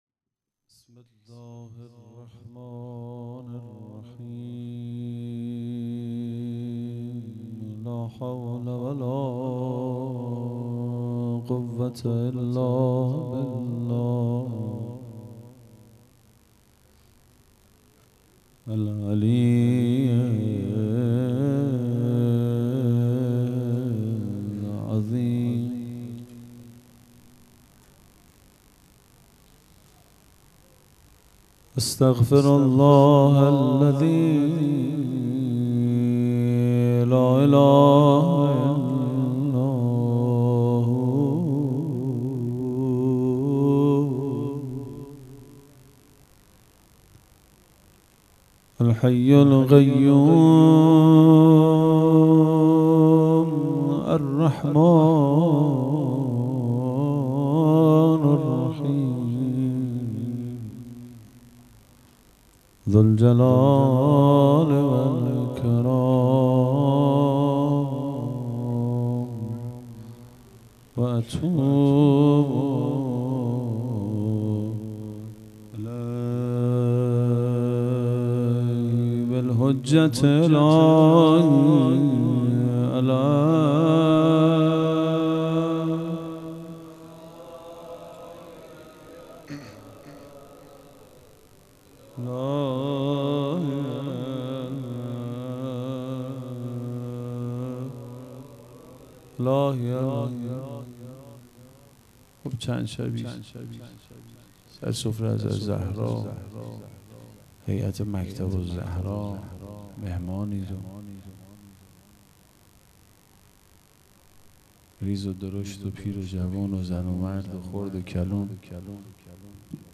هیئت مکتب الزهرا(س)دارالعباده یزد
0 0 روضه